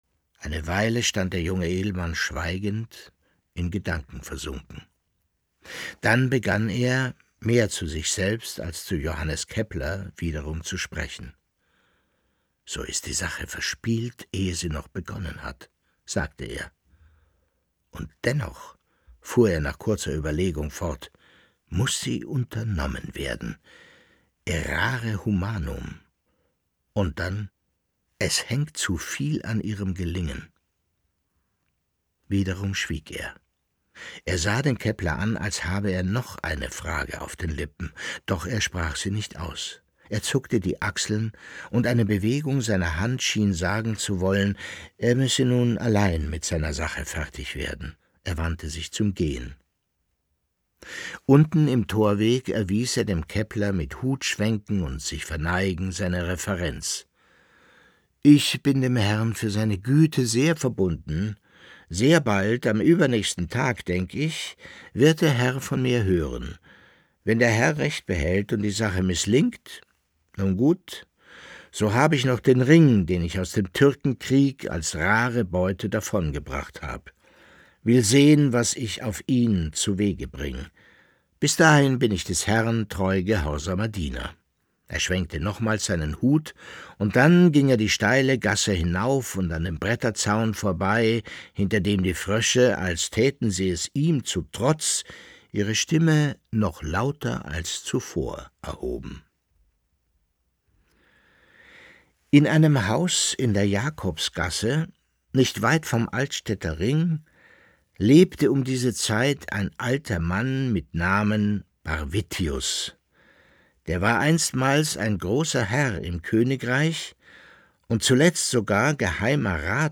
Leo Perutz: Nachts unter der steinernen Brücke (11/25) ~ Lesungen Podcast